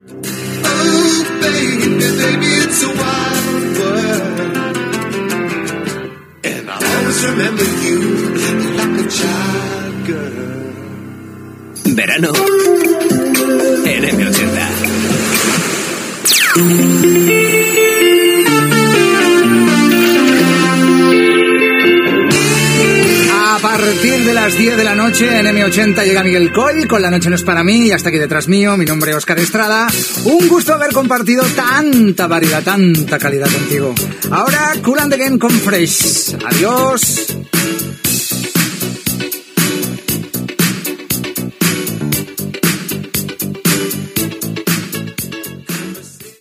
Tema musical, indicatiu, comiat del locutor. Gènere radiofònic Musical